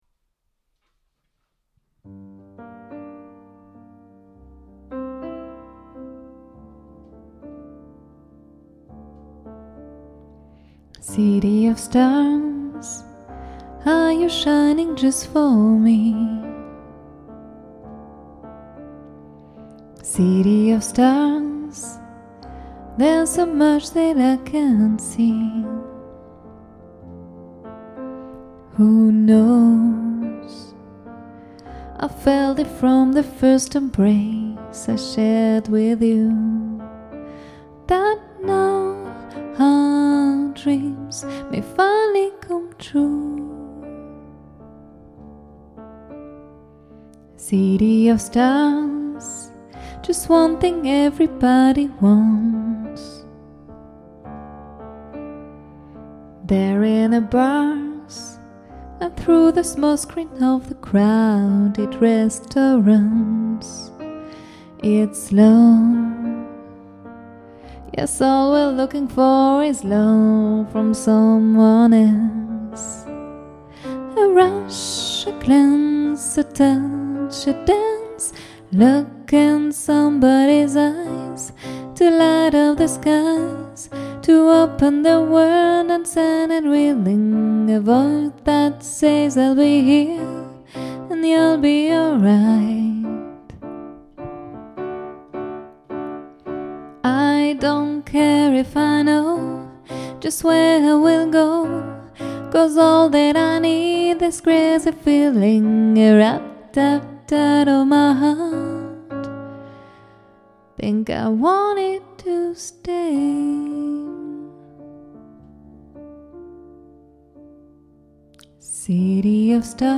Un duo Piano-Voix au répertoire jazz et variété